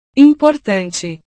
Cuando las palabras terminan en TE en portugués, se suele pronunciarlos como «chi».